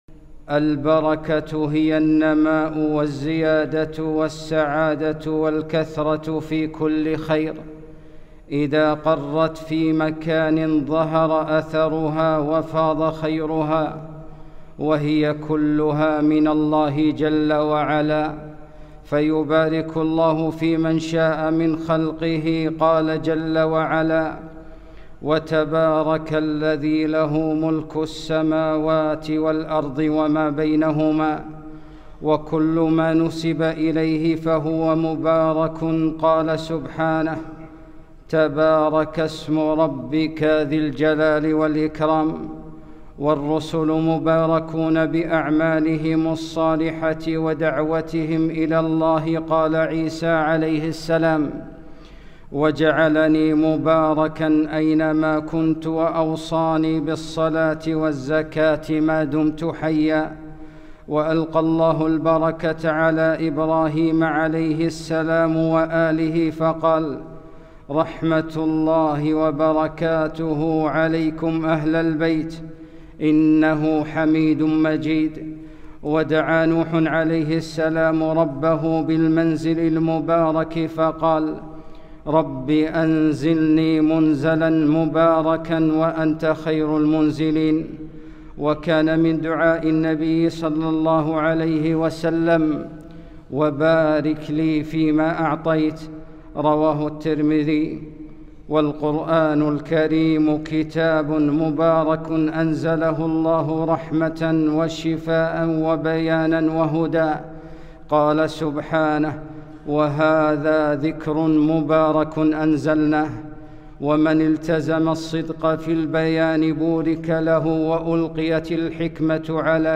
خطبة - وبارك لي فيما أعطيت